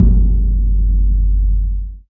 Index of /90_sSampleCDs/Roland LCDP03 Orchestral Perc/PRC_Orch Bs Drum/PRC_Grongkas